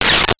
Space Gun 11
Space Gun 11.wav